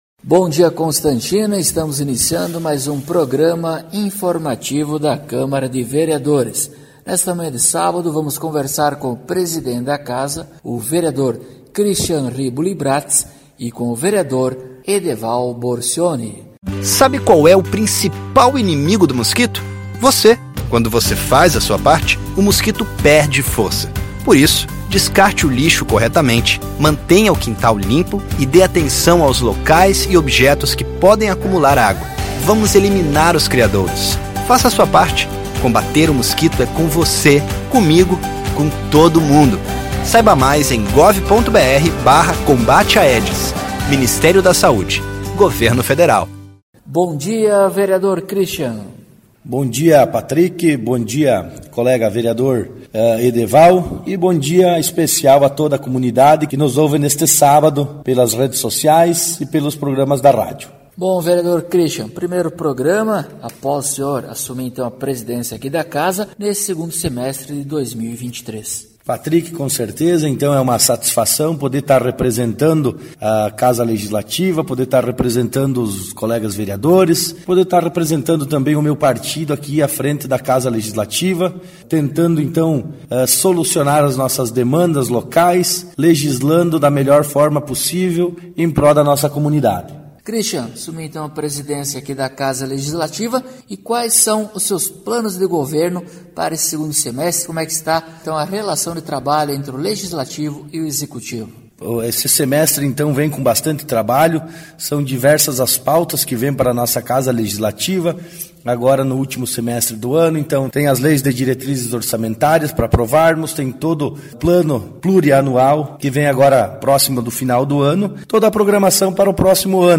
Acompanhe o programa informativo da câmara de vereadores de Constantina com o Vereador Cristian Bratz e o Vereador Edeval Borcioni.